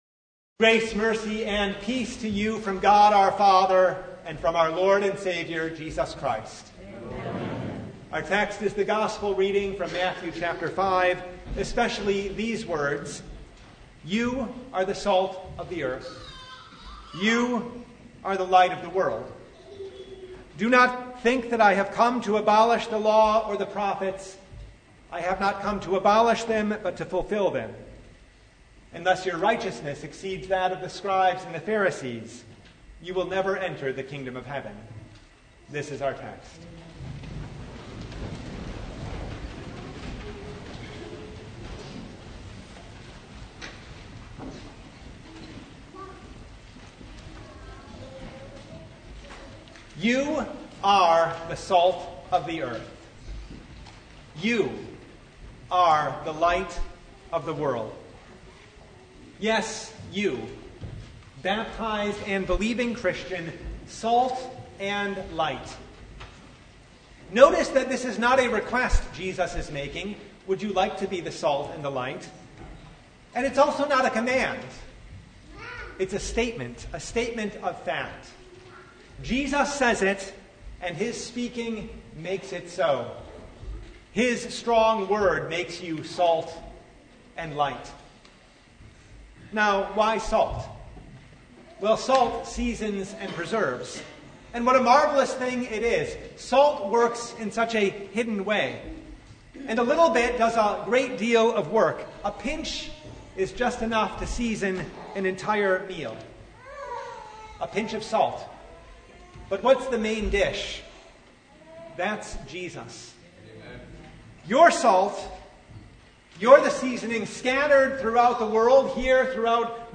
Matthew 5:13-20 Service Type: Sunday Jesus hasn’t come to abolish or relax the law and the prophets.